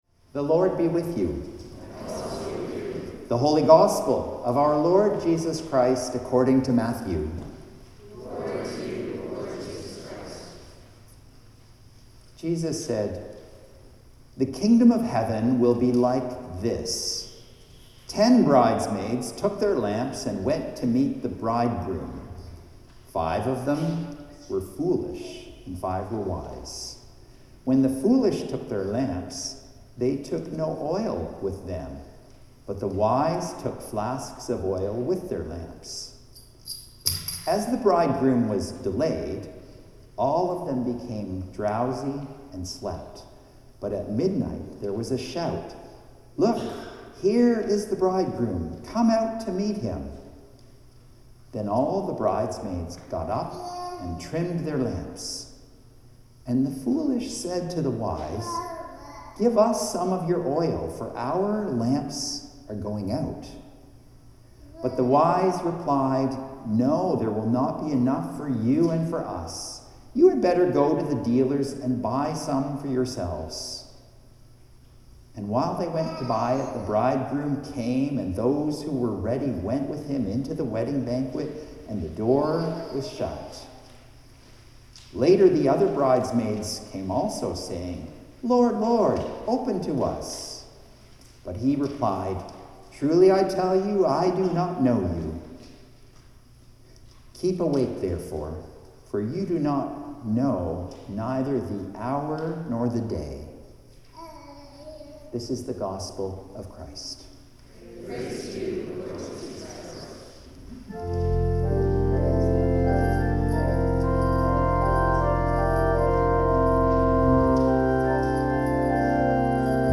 Sermons | St John the Evangelist